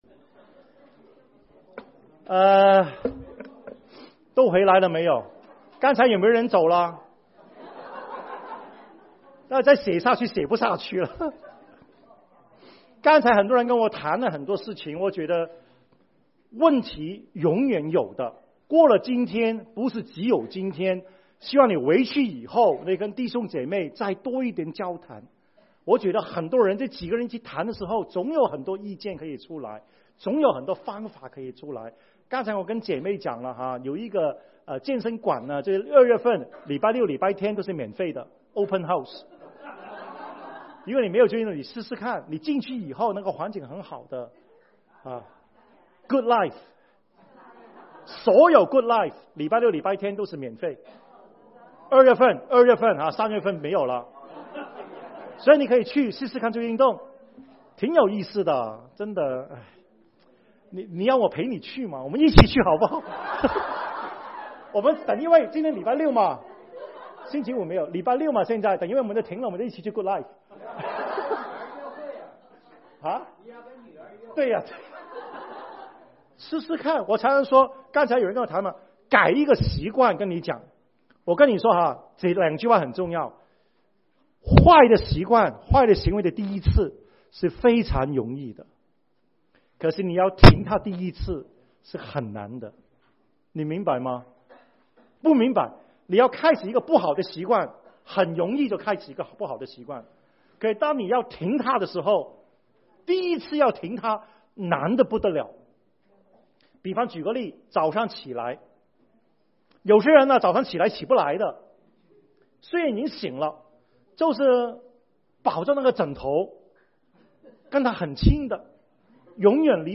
講座錄音 MP3 時間管理學 一 時間管理學 二 時間管理學 三 講義大綱 時間管理學 – 大綱 門徒訓練系列 – 時間管理學